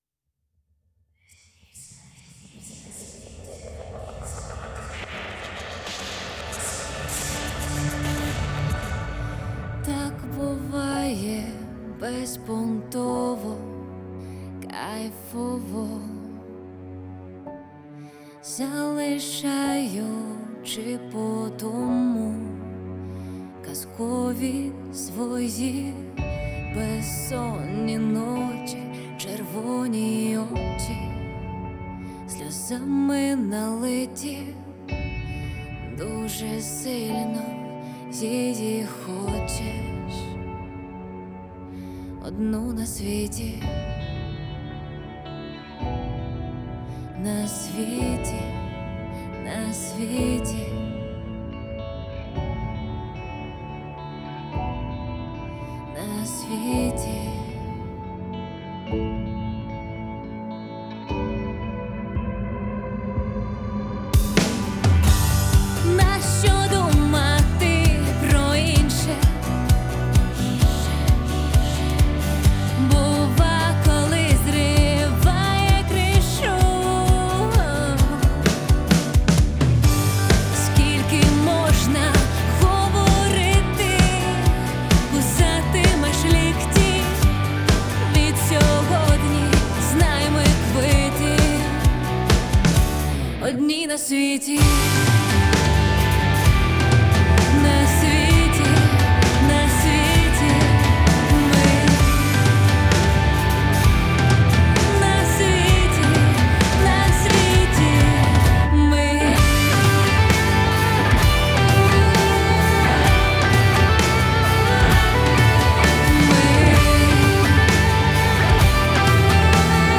поп-рок